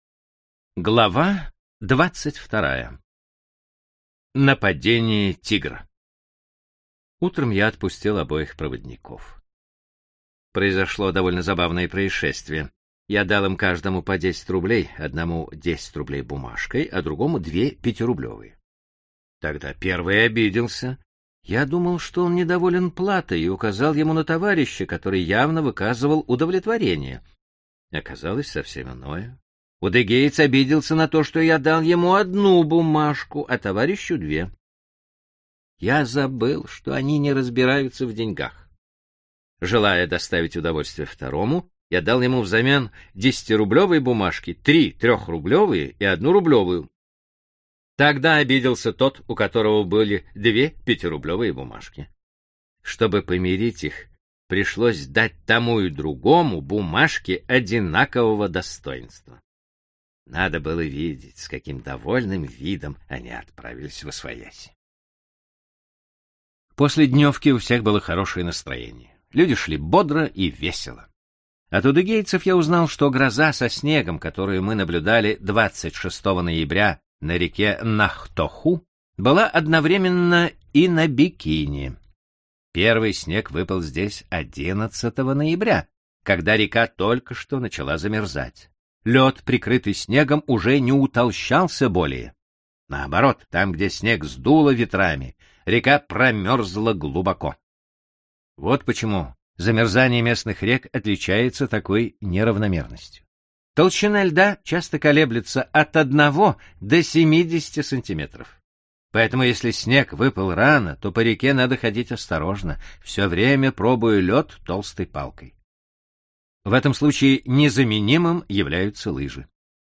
Аудиокнига Дерсу Узала
Качество озвучивания весьма высокое.